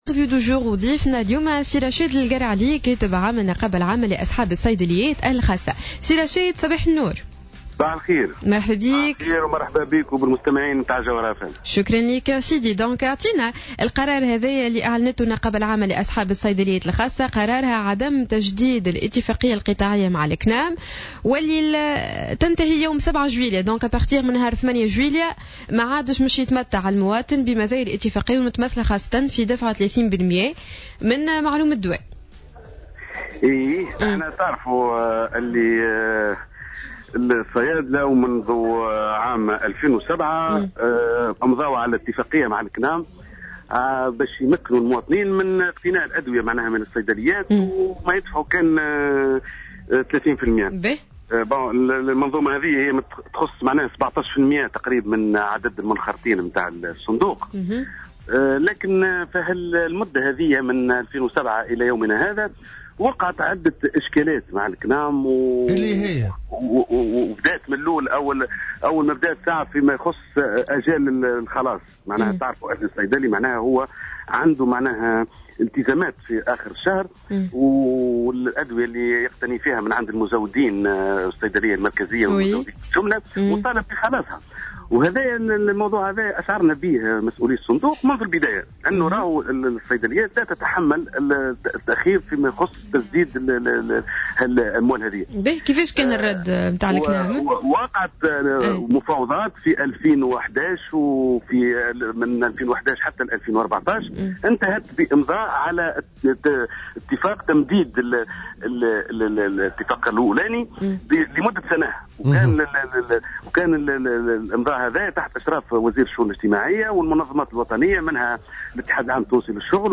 مداخلة على جوهرة "اف ام"